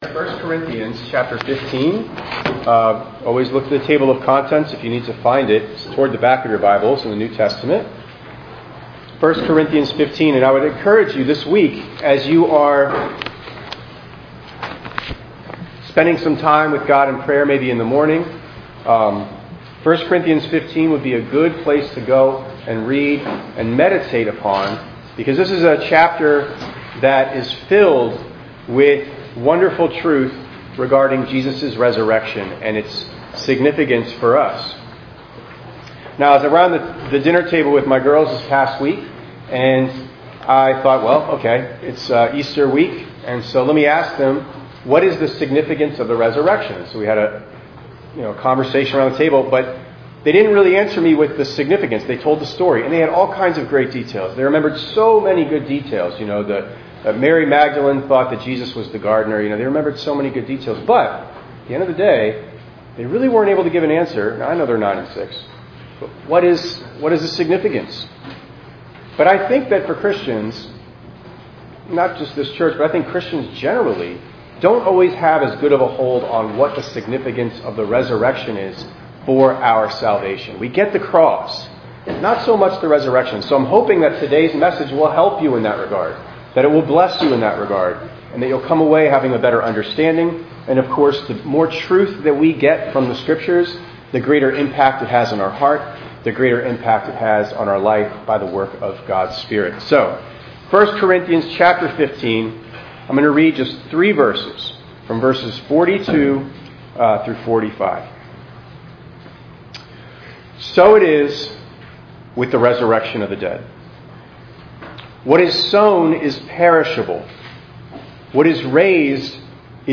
4_20_25_ENG_Sermon.mp3